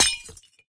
glass2